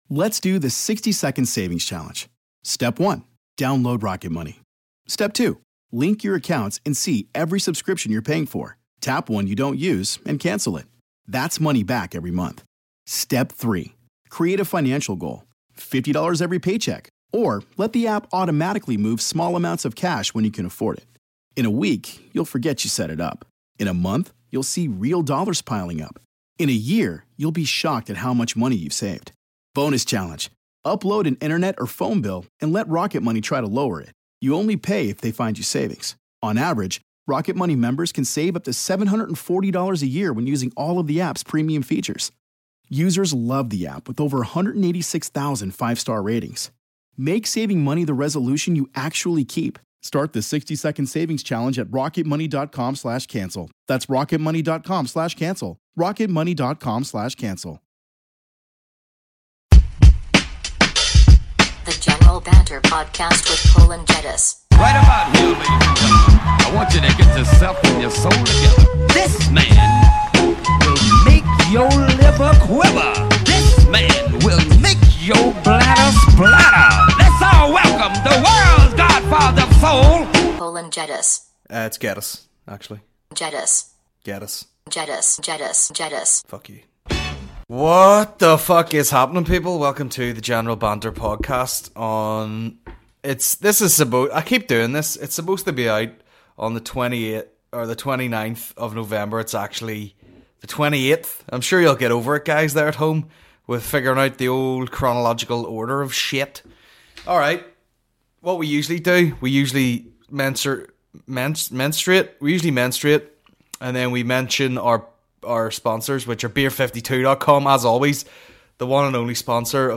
The General Banter Podcast is a Comedy podcast